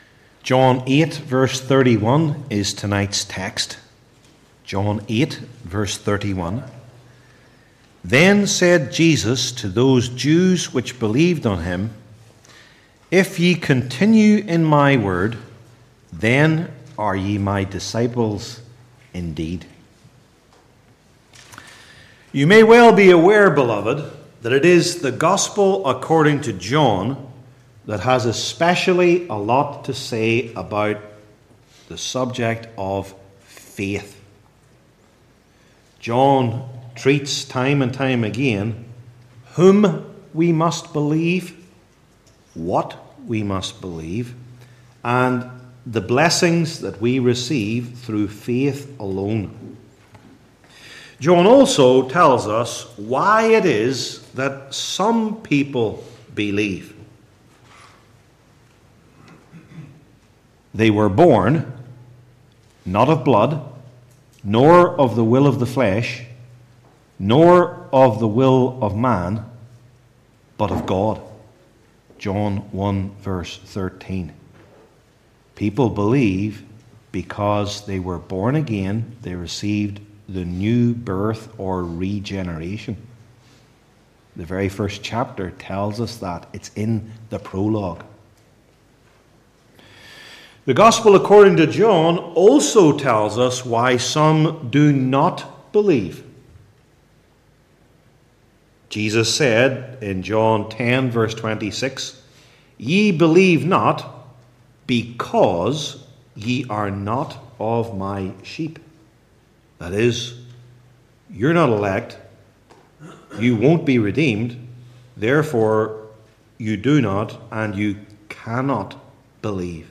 New Testament Individual Sermons I. What Jesus Presupposes II.